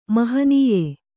♪ mahanīye